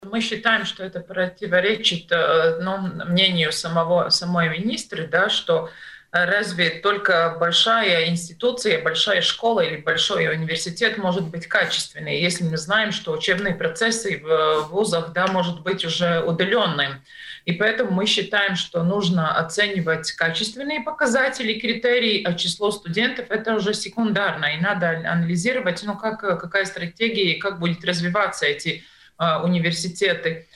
Сегодня в эфире радио Baltkom обсуждались такие темы, как работа фитнес-индустрии в условиях корона-кризиса, реформа вузов.